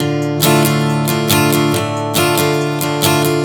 Strum 140 Dm 01.wav